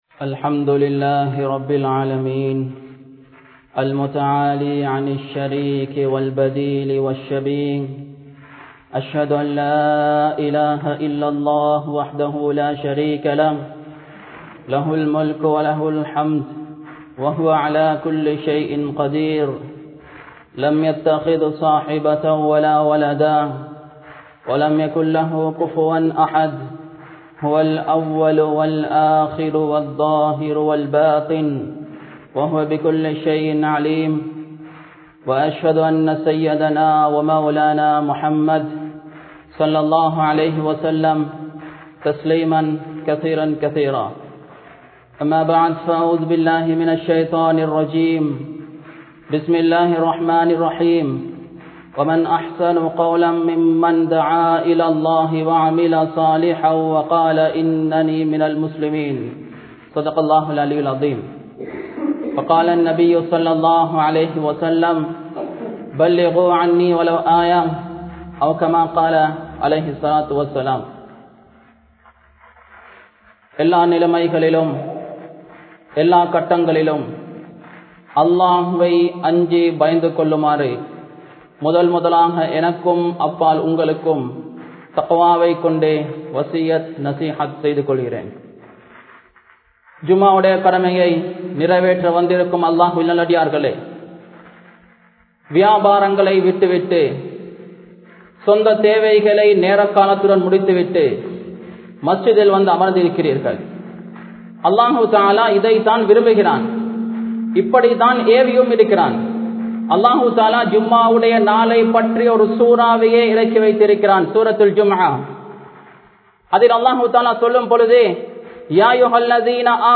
Hapugasthalawa Town Jumua Masjidh